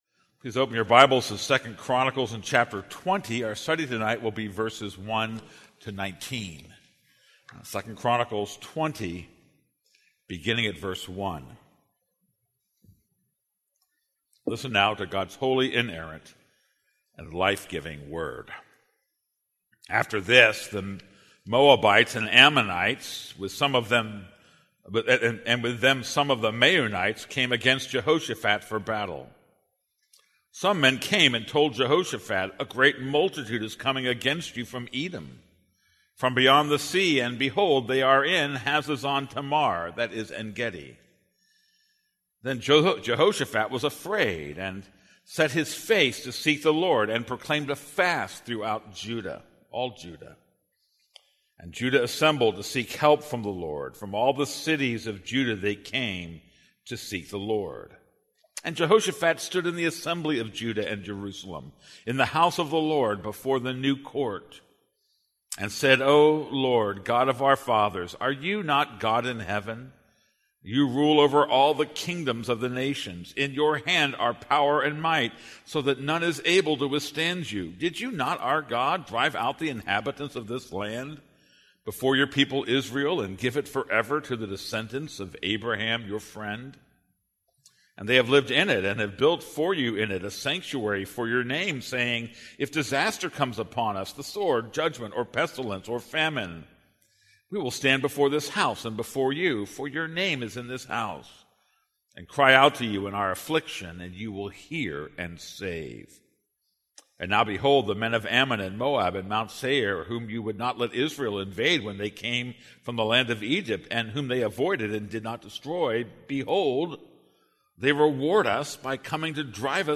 This is a sermon on 2 Chronicles 20:1-19.